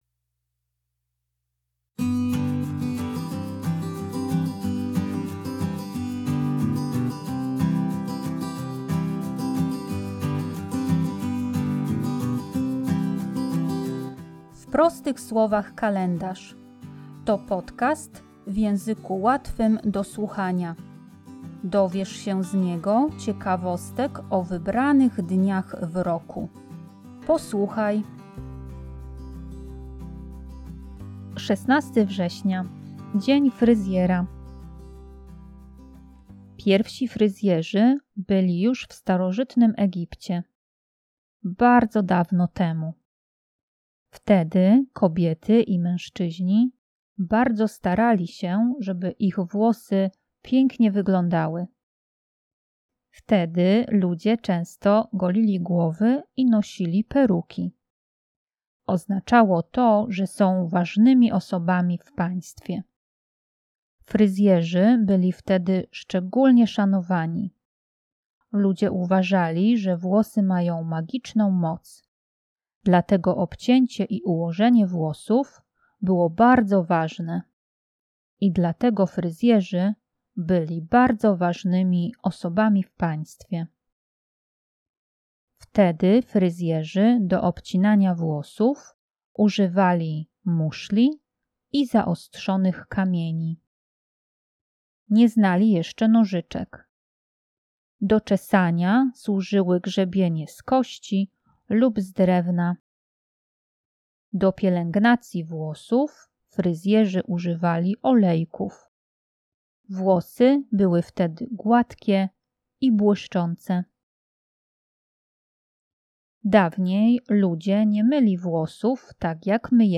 Tekst i lektorka